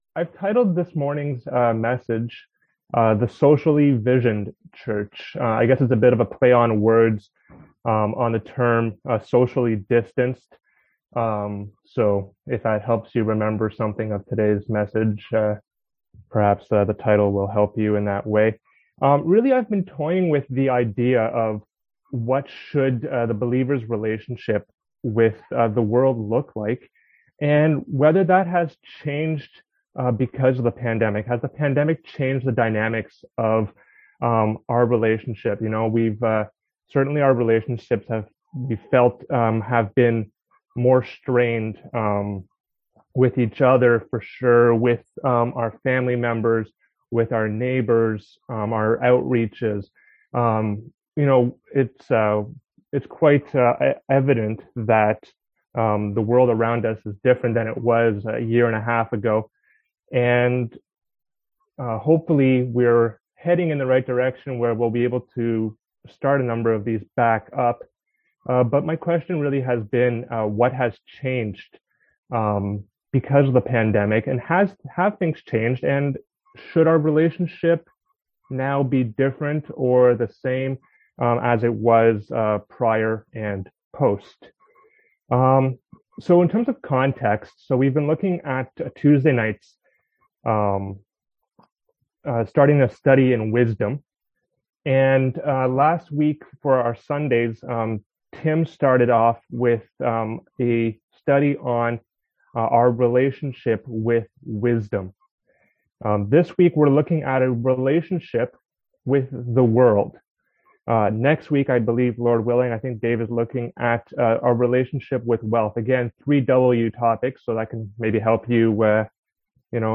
Passage: Psalm 119:65-72, Exodus 9:15-16 Service Type: Sunday AM